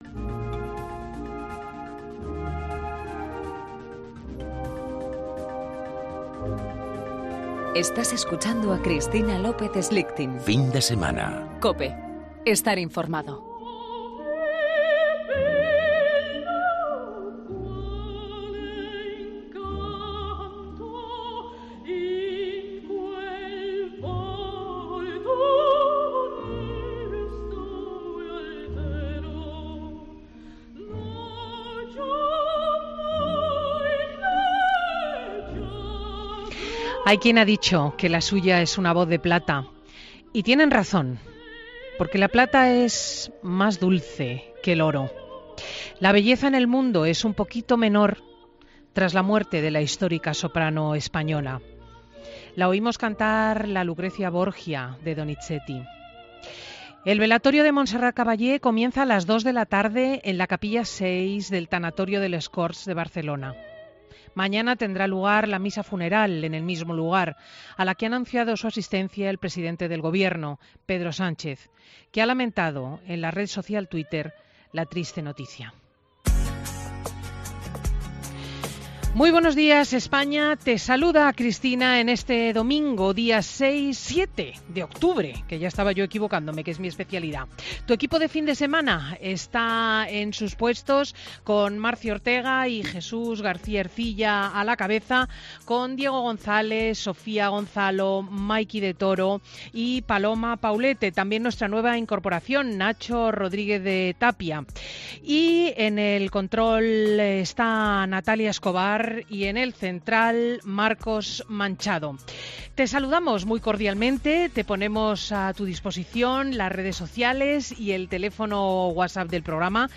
Monólogo de Cristina López Schlichting Schlichting.